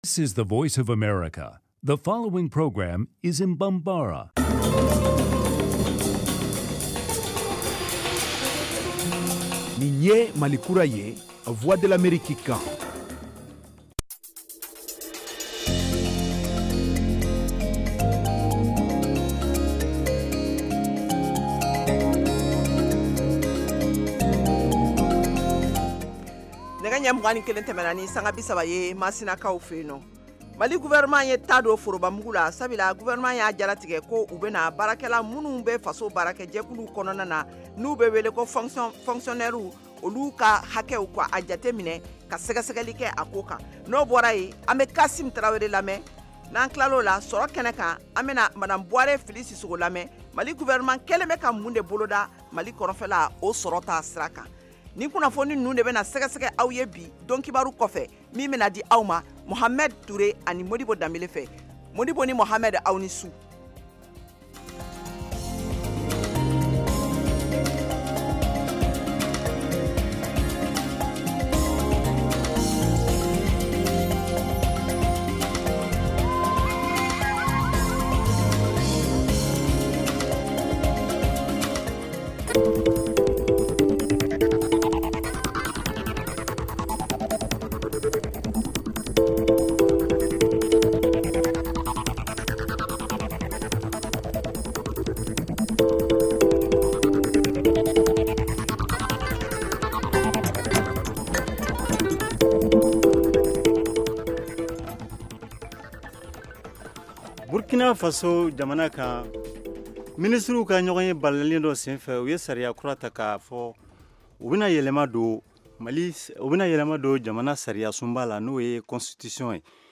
Emission quotidienne en langue bambara